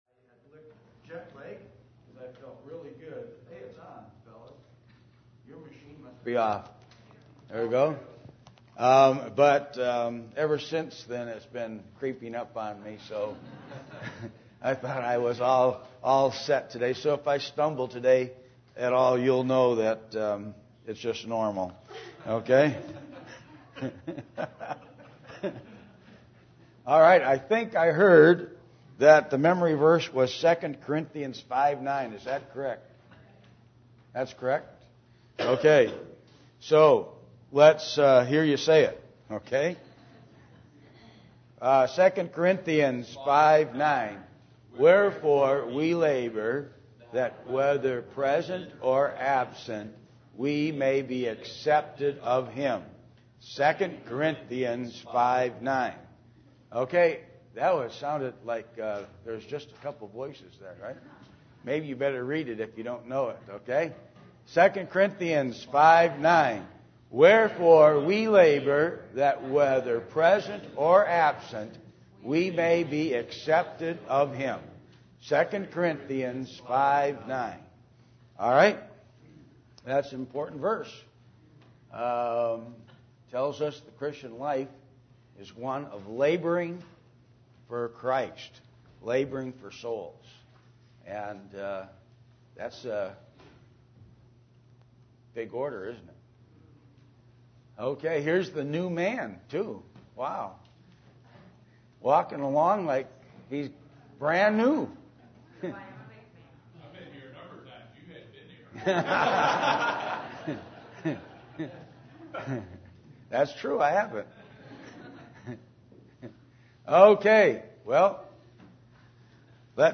Service Type: Adult Sunday School